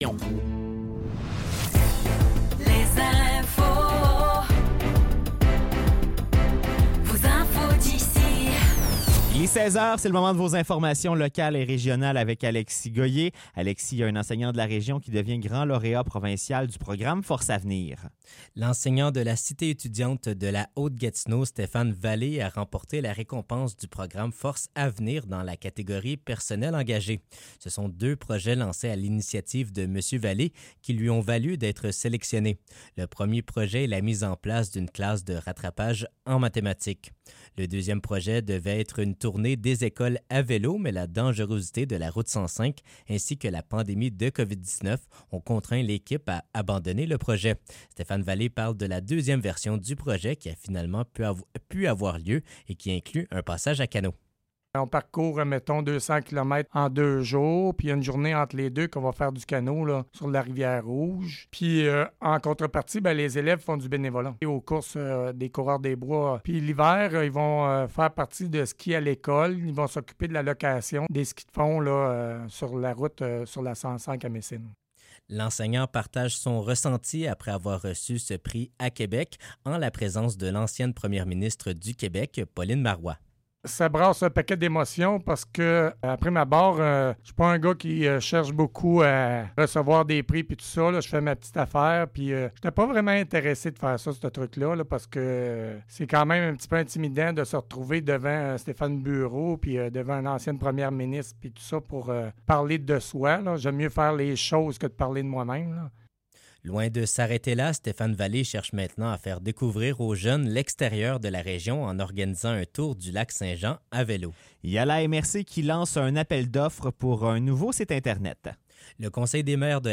Nouvelles locales - 8 octobre 2024 - 16 h